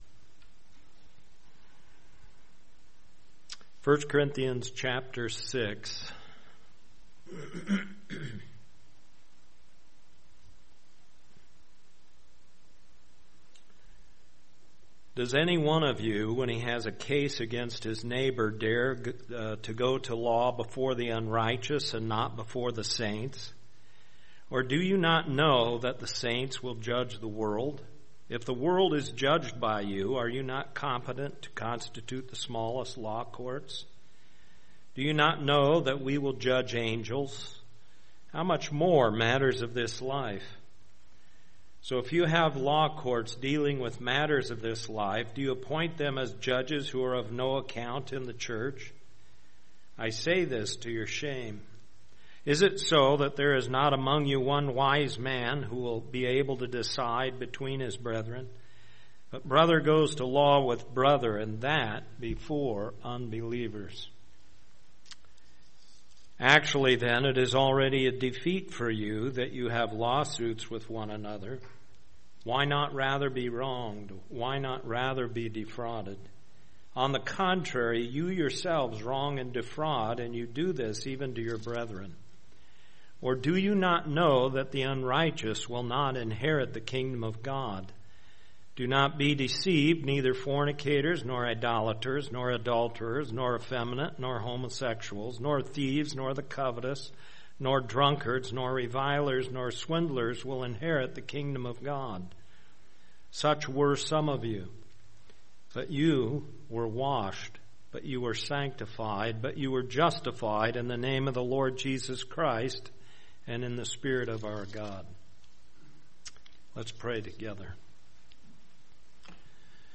Sermons | Lake Phalen Community Church